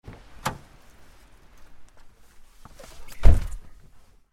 جلوه های صوتی
دانلود صدای ماشین 13 از ساعد نیوز با لینک مستقیم و کیفیت بالا